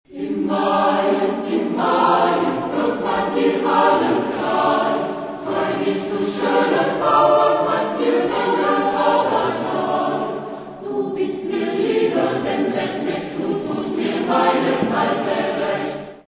Genre-Style-Forme : Renaissance ; Profane ; Madrigal ; Cantus firmus
Caractère de la pièce : spirituel
Type de choeur : SATB  (4 voix mixtes )
Tonalité : mode de ré
Réf. discographique : Brasil 2002 Live